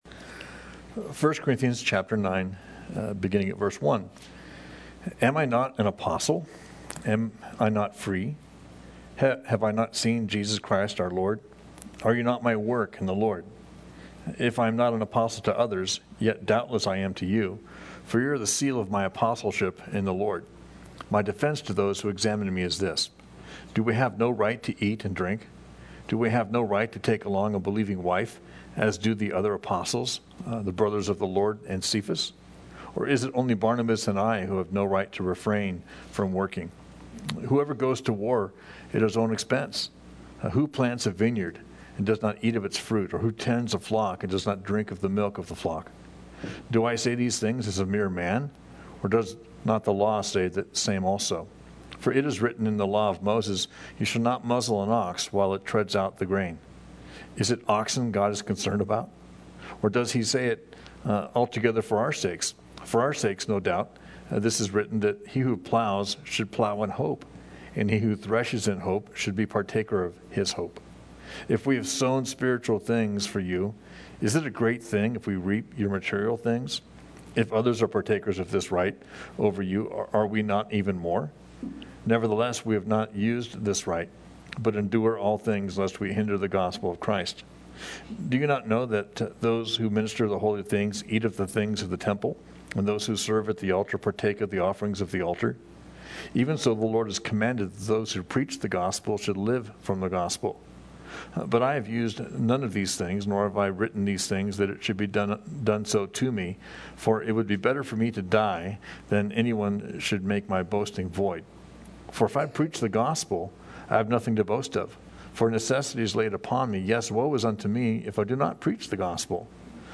Thank’s for checking out our study in 1st Corinthians.